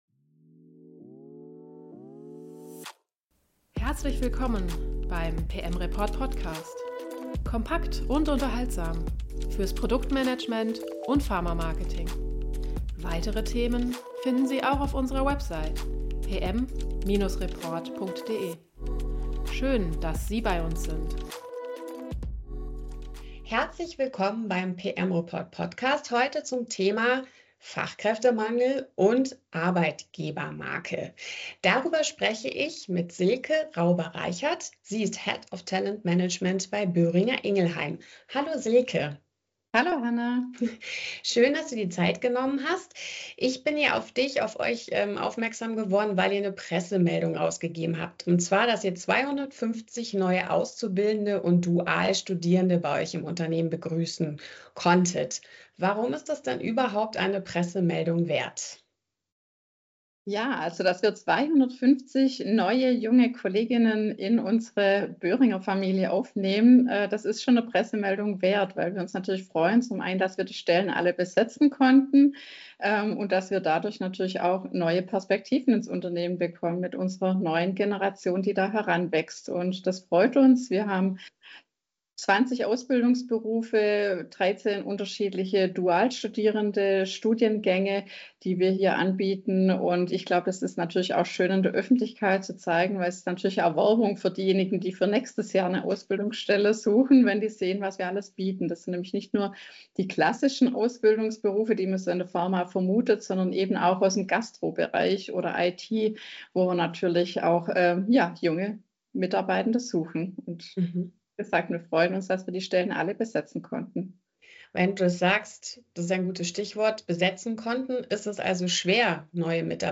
Wie können sich Pharmaunternehmen als einen sinnstiftenden Arbeitgeber positionieren? Im Gespräch